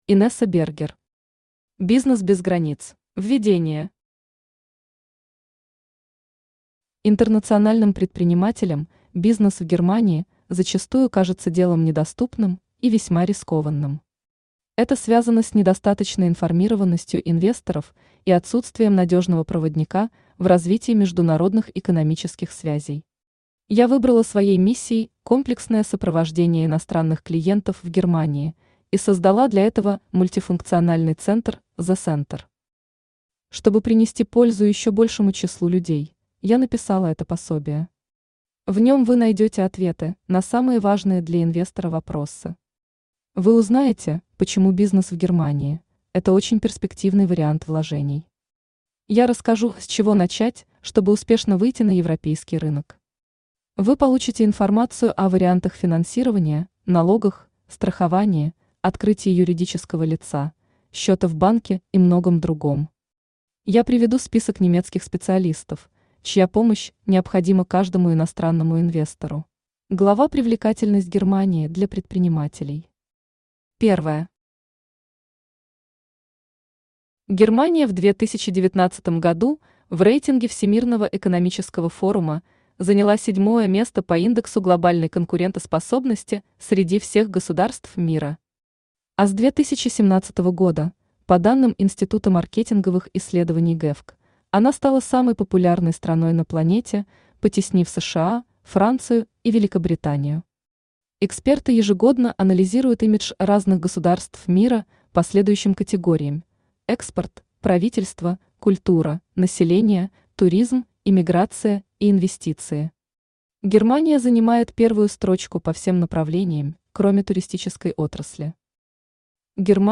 Аудиокнига Бизнес без границ | Библиотека аудиокниг
Aудиокнига Бизнес без границ Автор Инесса Бергер Читает аудиокнигу Авточтец ЛитРес.